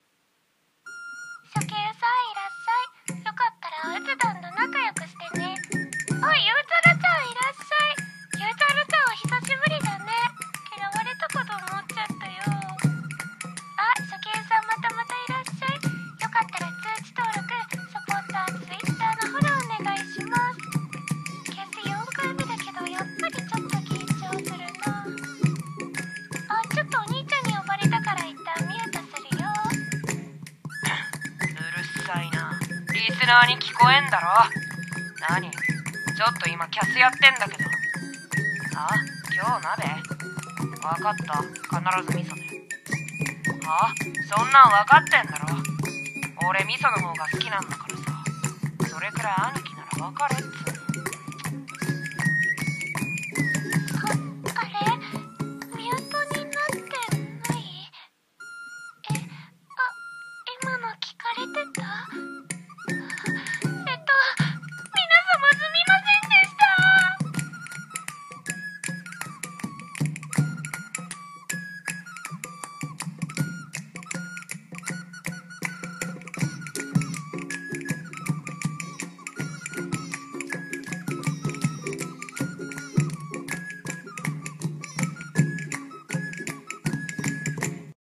【声劇】萌え声が地声バレる【1人声劇】